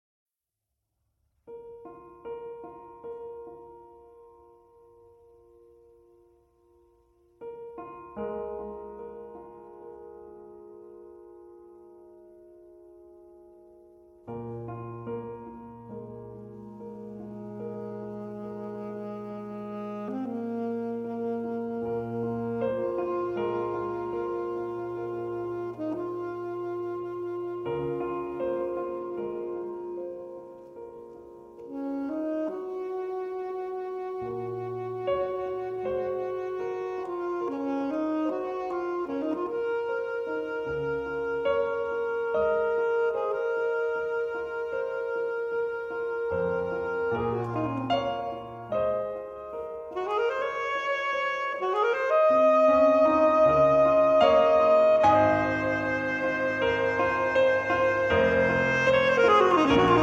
saxophone
piano